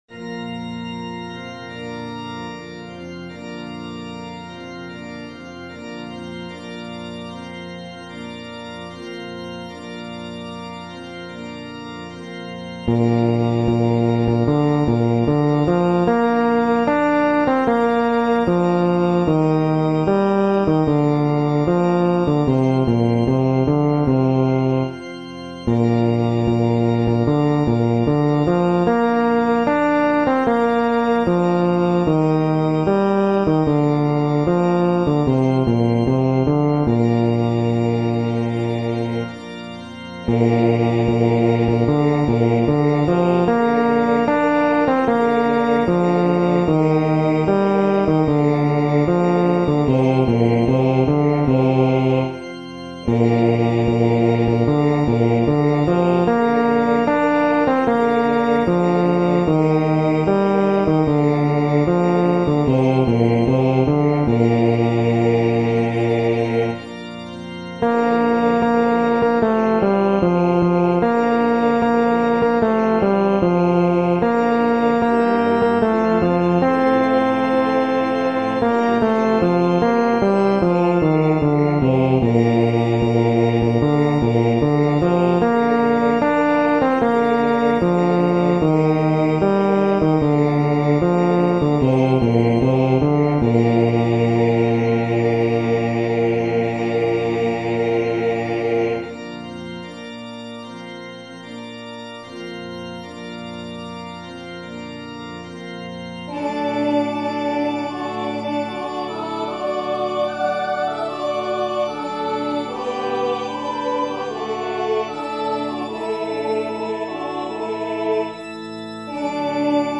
テノール（フレットレスバス音）